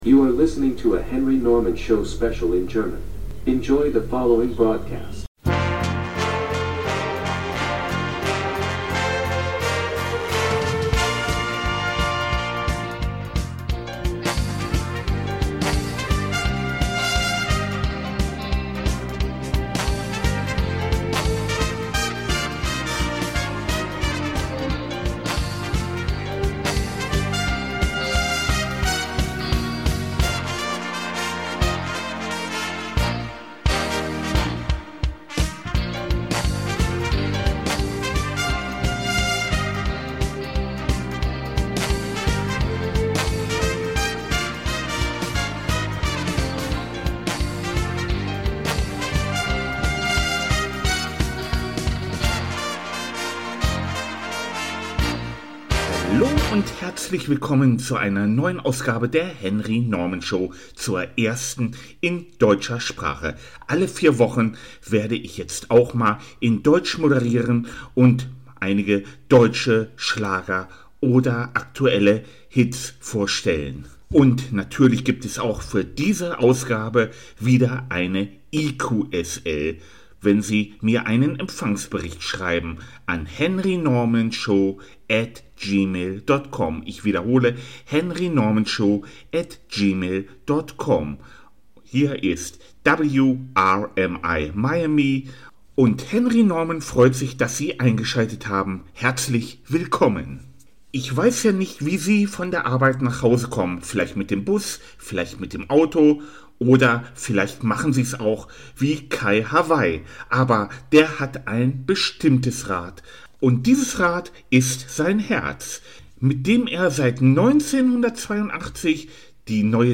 moderiert ein Spezial seiner Show in deutscher Sprache mit deutscher Musik